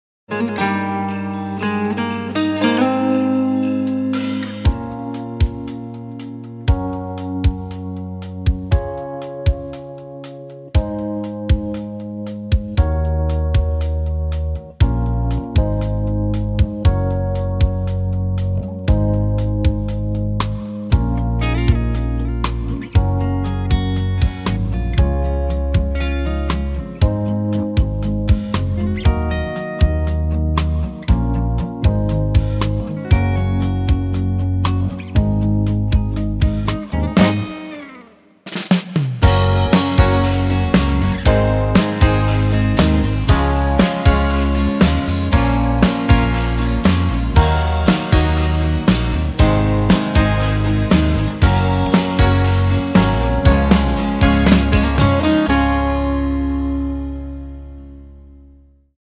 Kolędowa Moc – wersja pełna Kolędowa Moc – wersja instrumentalna (Challenge) Kolędowa Moc – wersja instrumentalna (wersja pełna)